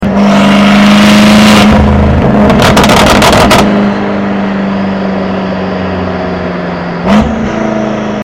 😈 BMW M8, Akrapovic Sounds sound effects free download